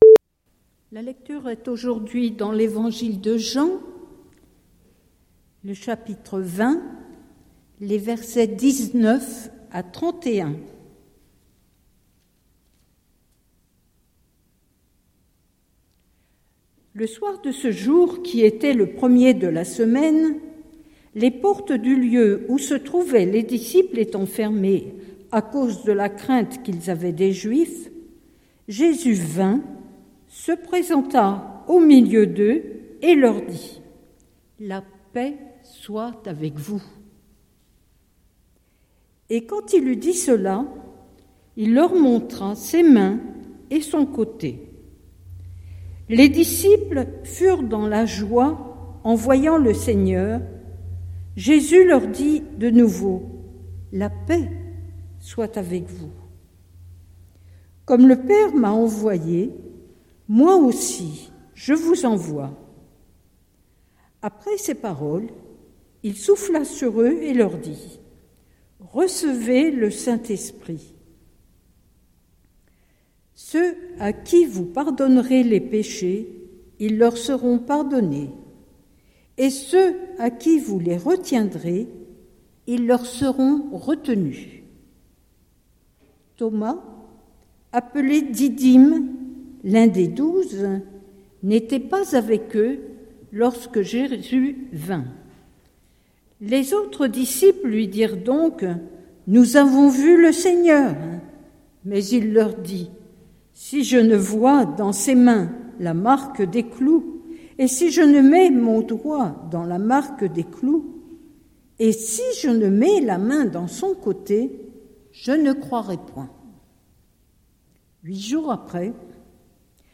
Prédication du 27/04/2025